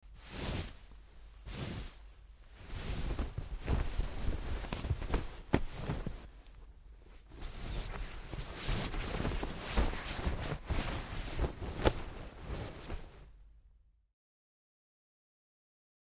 На этой странице собраны звуки, связанные с одеялом: шуршание ткани, легкое движение, уютное тепло.
Звук: перетягиваем одеяло на себя